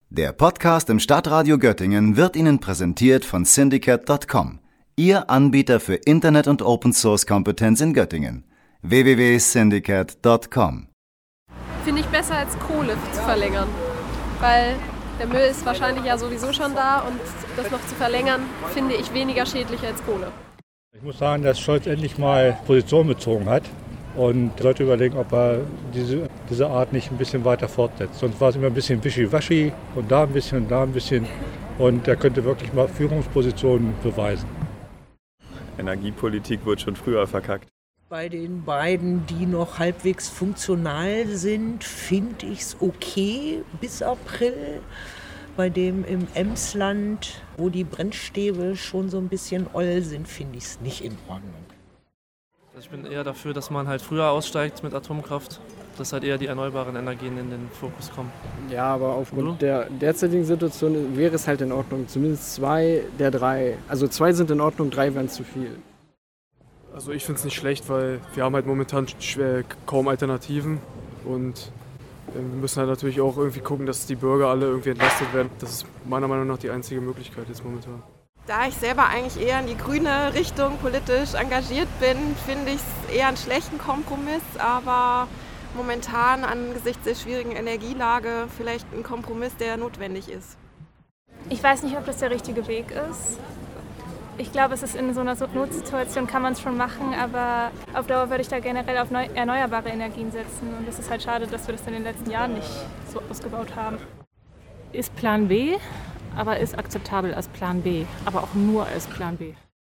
haben die Göttinger und Göttingerinnen gefragt, was sie von Olaf Scholz Entscheidung halten, insgesamt 3 Atomkraftwerke bis Frühling nächsten Jahres in Betrieb zu halten.